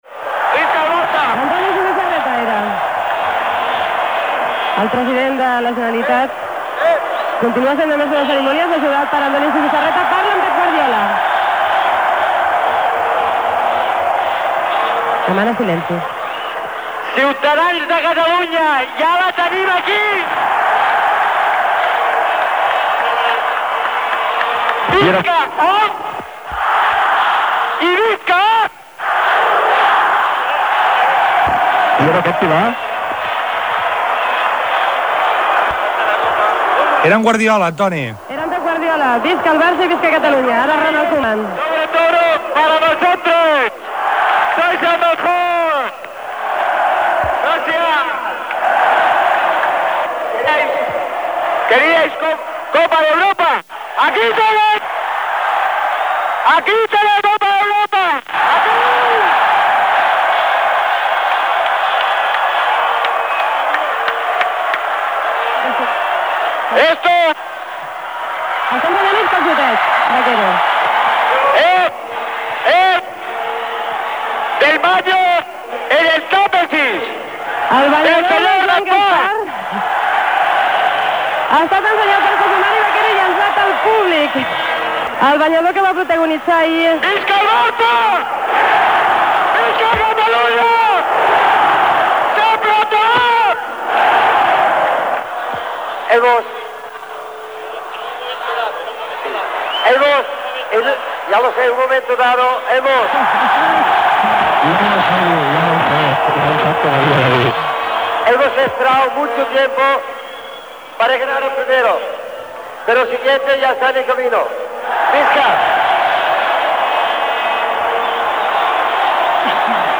Transmissió de la rebuda de l'equip masculí de futbol del F.C:Barcelona després de guanyar la Copa d'Europa. Paraules, des del balcó del Palau de la Generalitat, a la plaça Sant Jaume de Barcelona, dels jugadors Josep Guardiola, Ronald Koeman, José Mari Baquero, de l'entrenador Joahn Cruyff i del president Josep Lluís Núñez
Esportiu